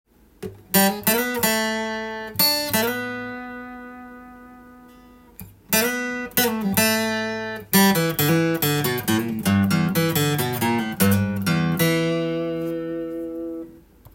この曲のリードギターのメロディーは意外と細かいのが特徴です。